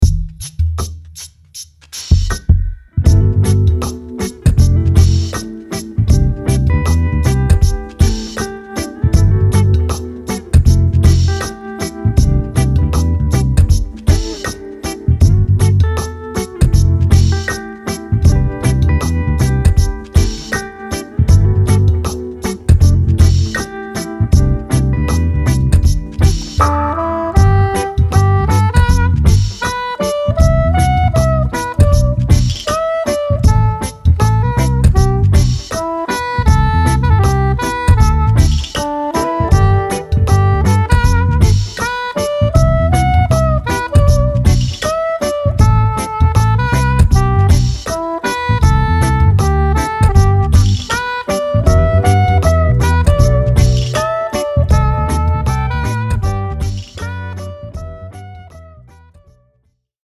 ウォーミーなジャズ・レゲエ・インスト
Saxophone,Flute
Bass & Loop
Guitar